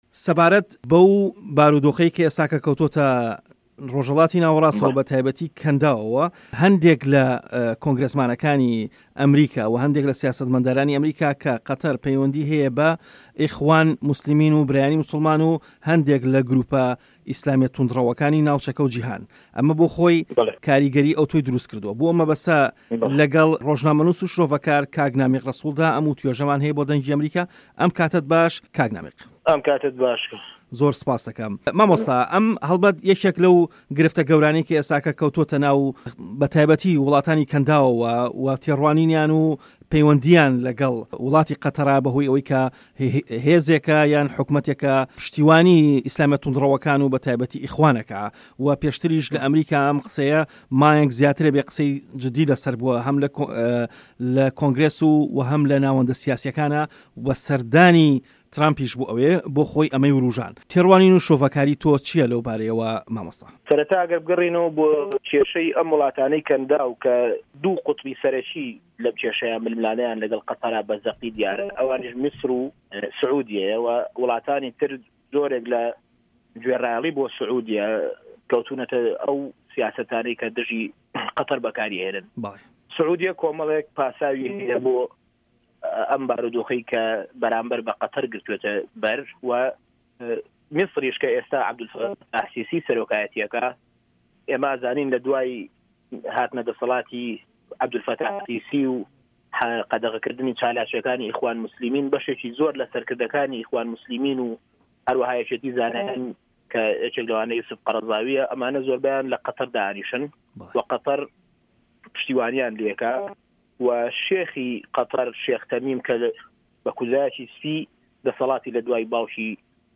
ڕۆژهه‌ڵاتی ناوه‌ڕاست - گفتوگۆکان